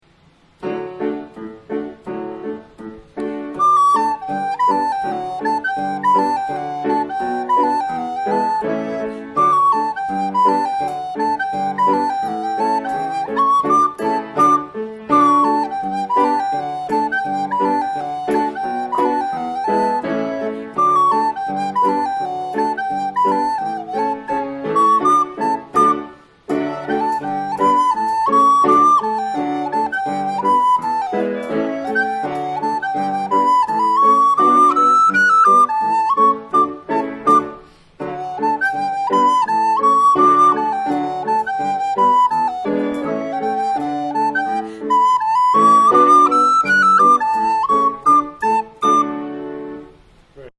Reel - D Major